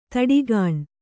♪ taḍigāṇ